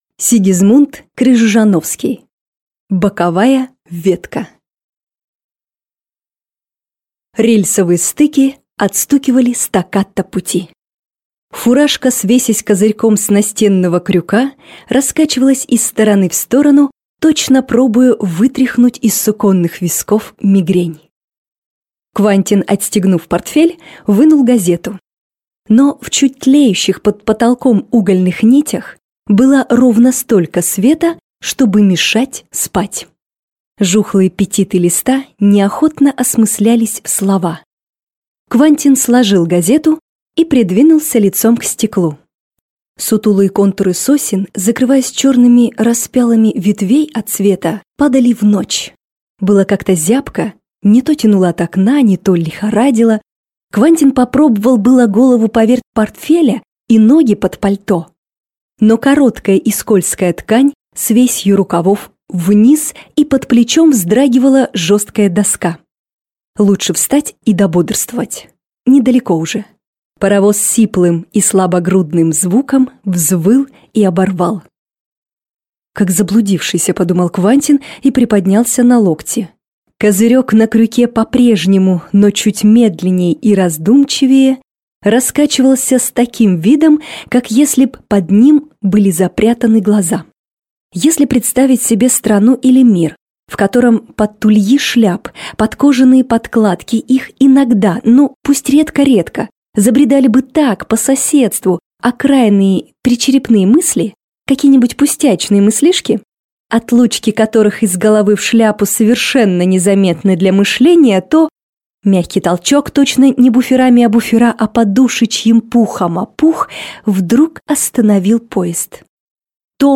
Аудиокнига Боковая ветка | Библиотека аудиокниг